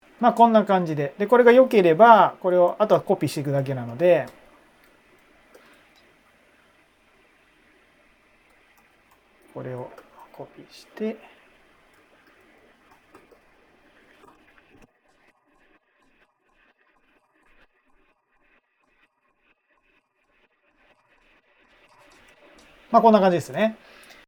そしてこちらがエアコンの音をなくすためにRX 7で処理したものです。
この喋りの裏側で鳴っているエアコンの音が少しボリュームダウンしています。
先ほどのサンプルではさらに「Music Rebalance」という機能も使って、声以外の音のボリュームを絞ってます。
rx7-noise-test-after.mp3